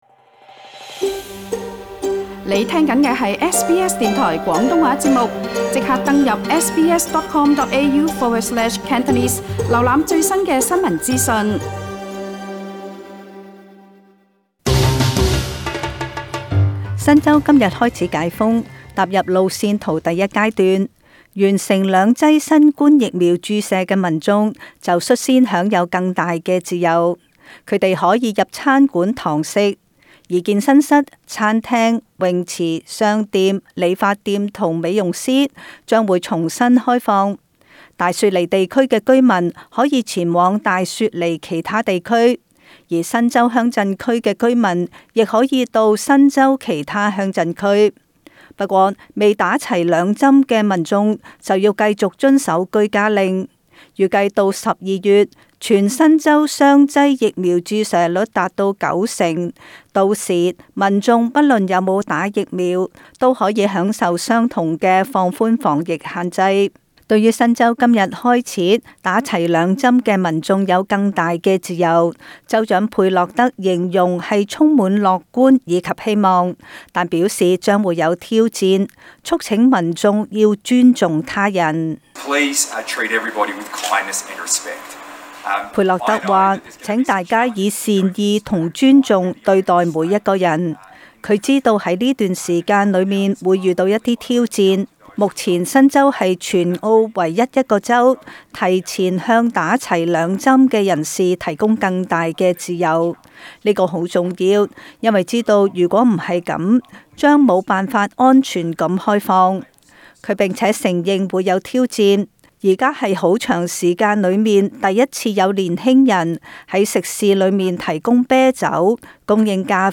【時事報道】